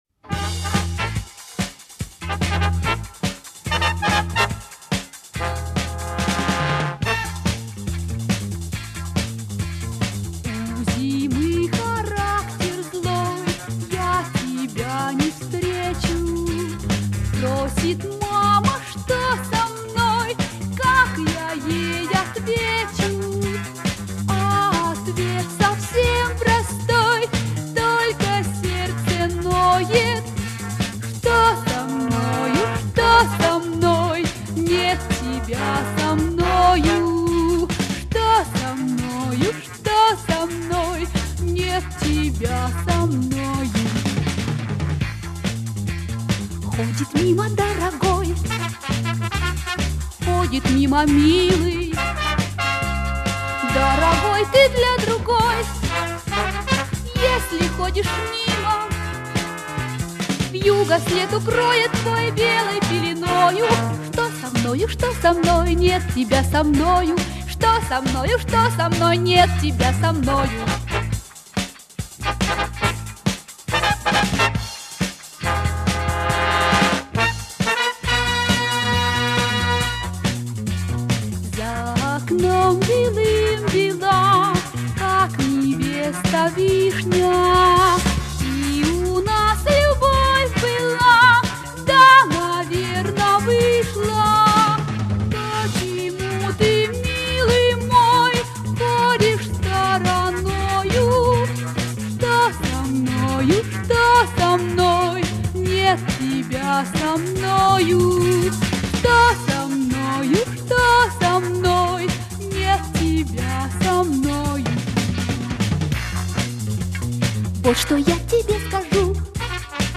Просьба помочь найти имя солистки.